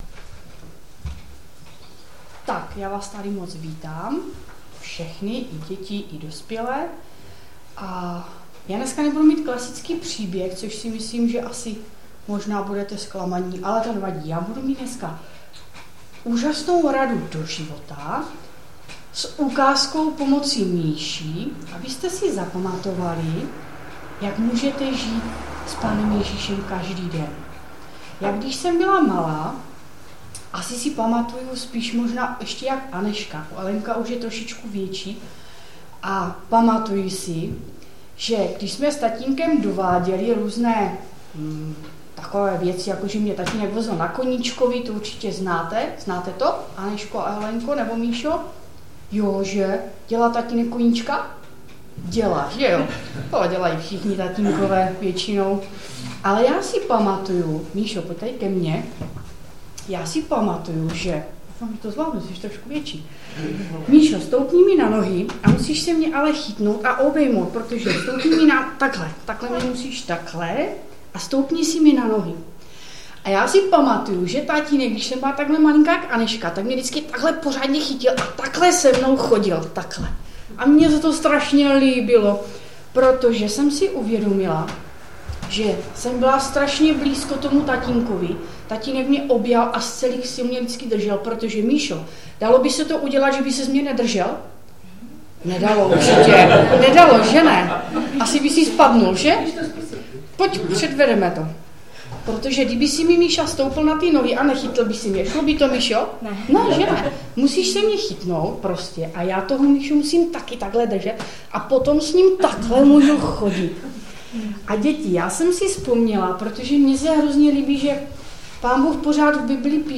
Kázání sboru CASD Vrbno pod Pradědem